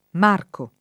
marcare